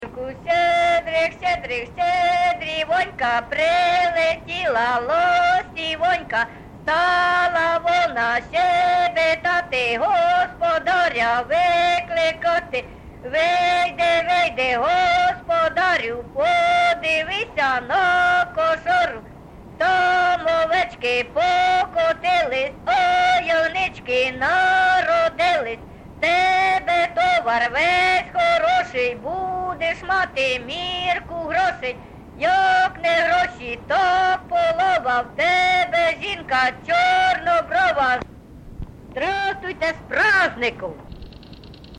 ЖанрЩедрівки
Місце записус. Харківці, Миргородський (Лохвицький) район, Полтавська обл., Україна, Полтавщина